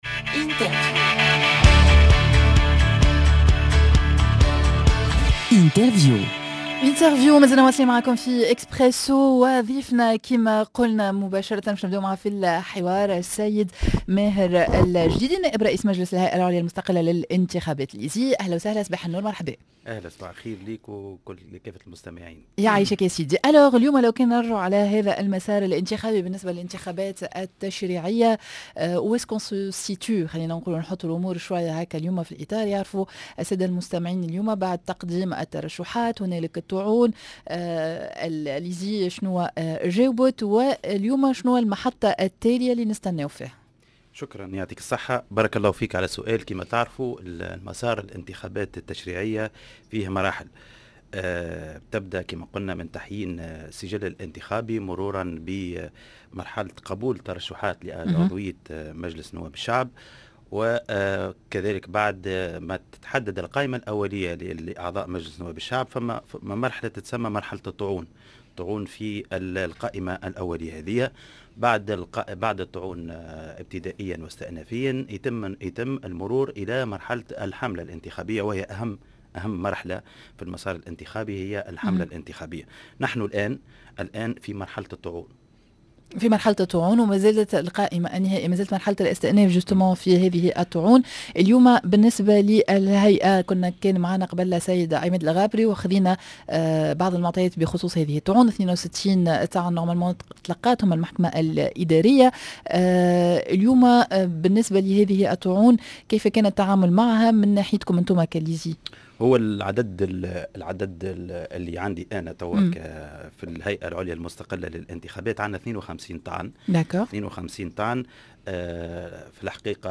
L'interview:ماذا عن القرار المشترك بين الهايكا و الإيزي؟ نائب رئيس مجلس الهيئة العليا المستقلة للانتخابات ماهر الجديدي